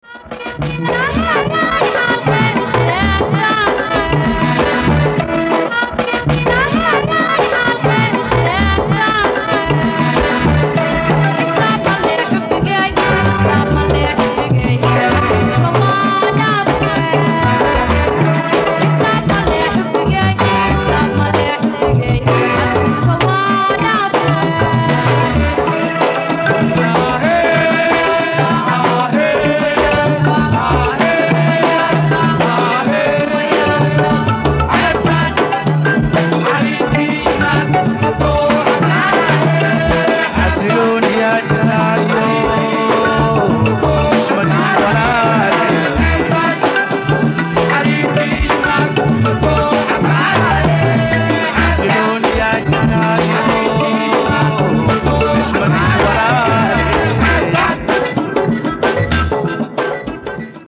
Digitized from cassettes